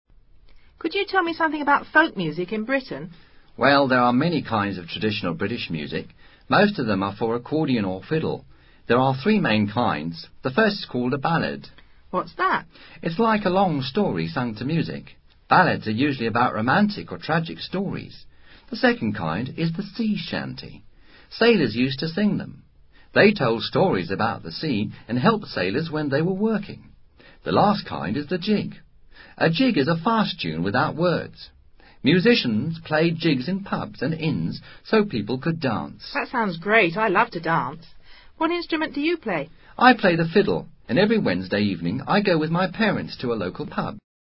Diálogo sobre la música tradicional en Gran Bretaña.